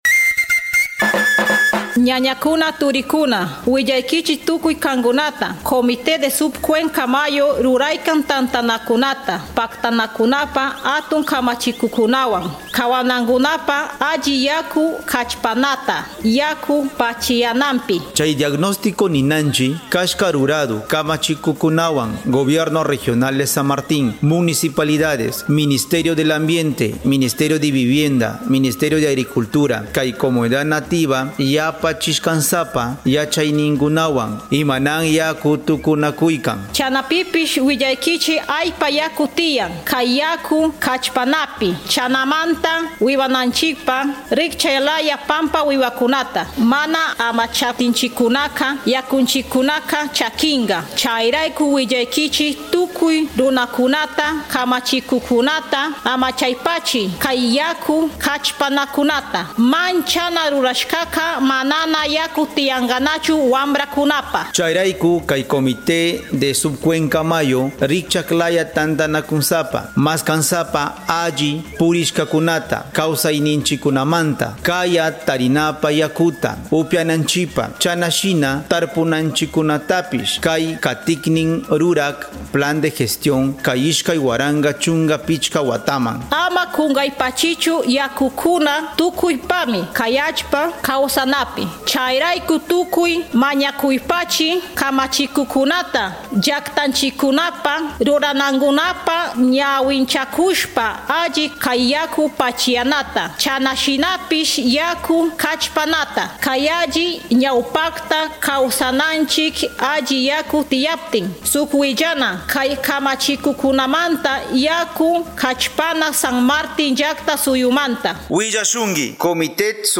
Spot: Validación del Diagnóstico de la gestión de los recursos hídricos de la cuenca del río Mayo - Kichwa | Mayo